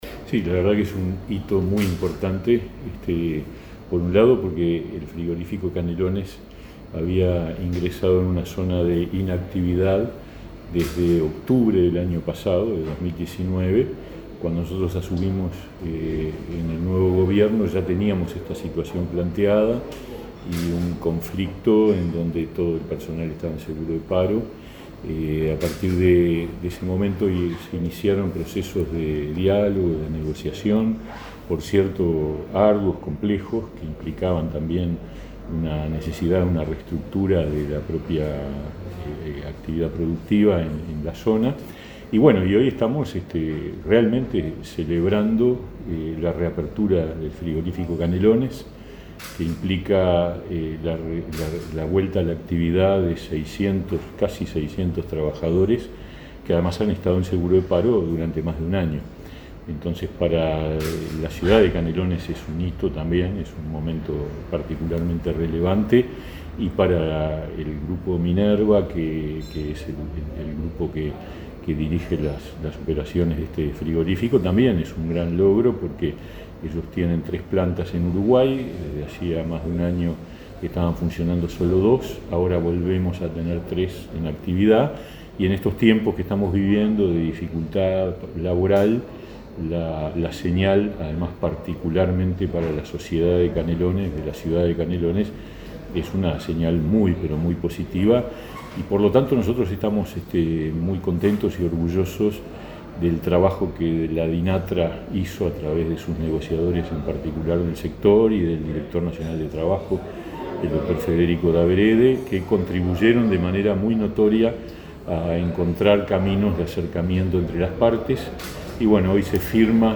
Entrevista con el ministro de Trabajo y Seguridad Social, Pablo Mieres